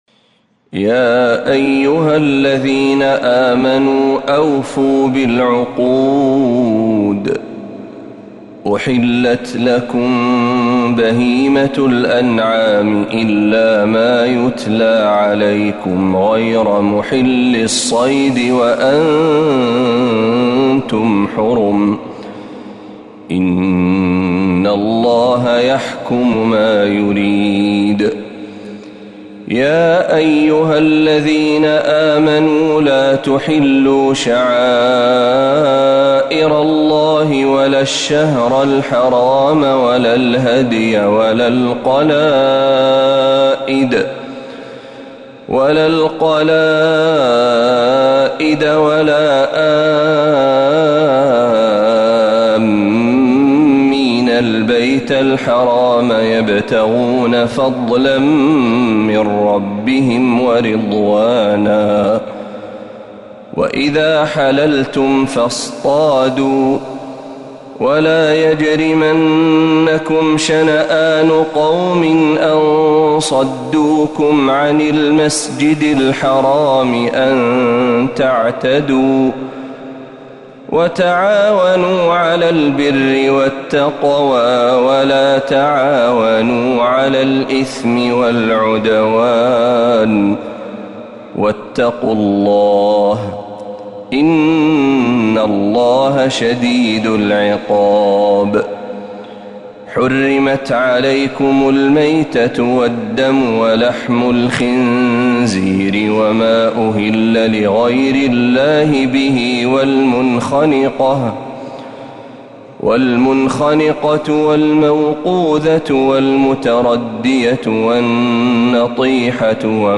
سورة المائدة كاملة من الحرم النبوي